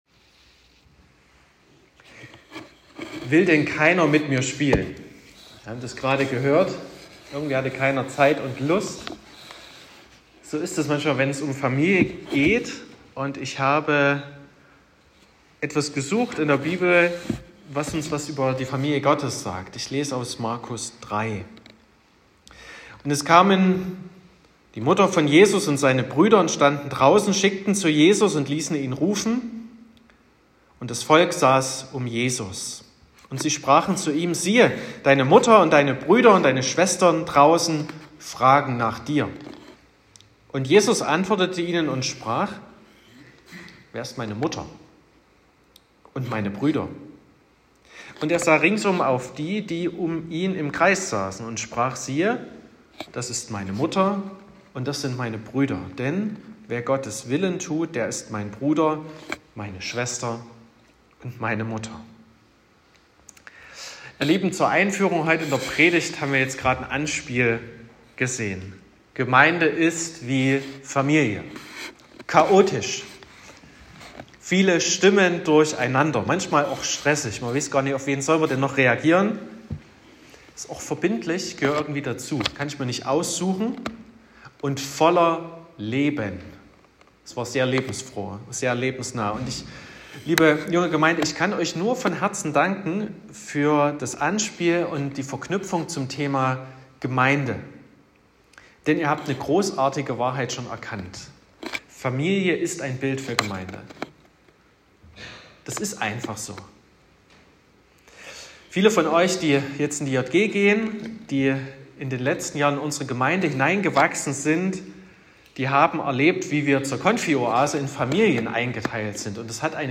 Herzliche Einladung zu einem besonderen Gottesdienst, ausgestaltet von unserer Jungen Gemeinde.
Predigt und Aufzeichnungen